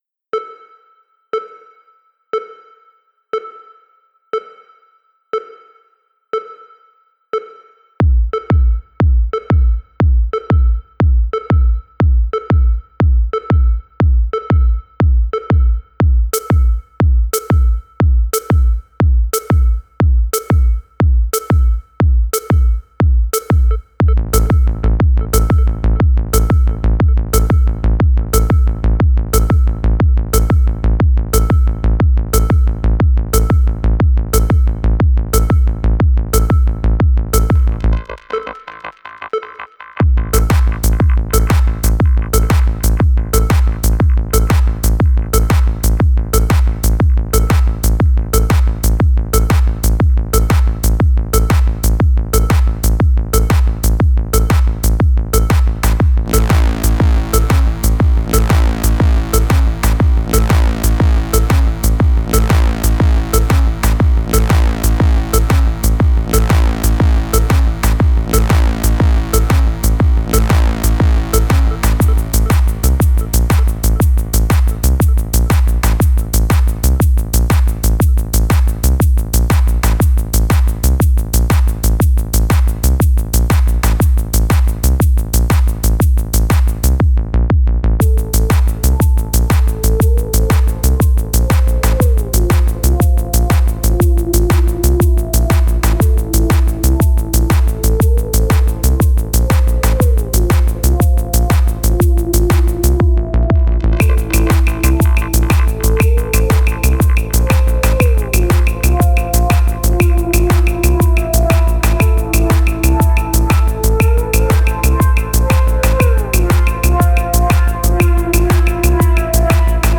Genre: Trance Progressive